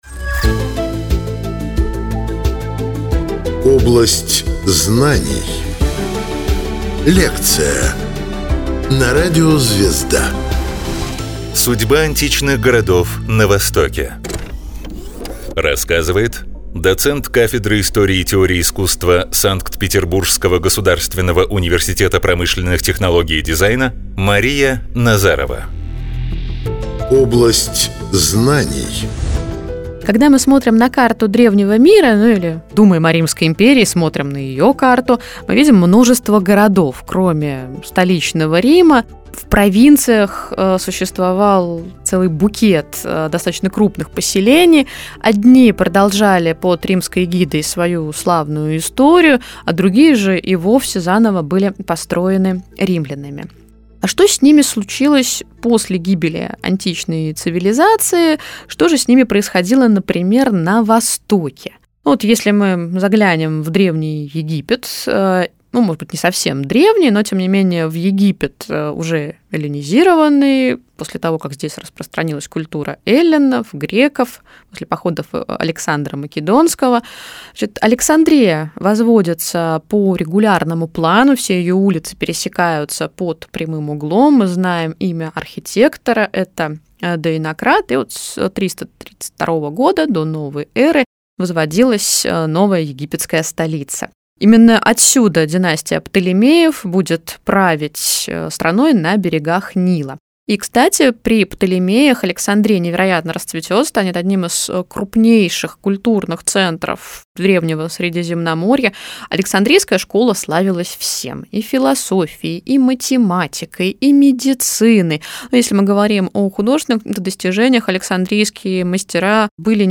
Что на самом деле означает эксперимент Шрёдингера? Лекция физика